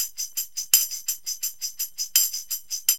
TAMB LP 84.wav